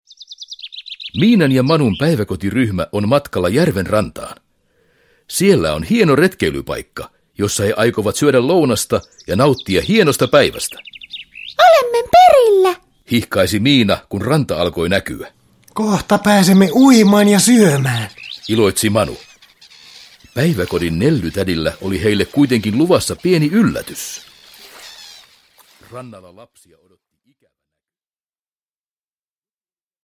Miina ja Manu kierrättävät – Ljudbok – Laddas ner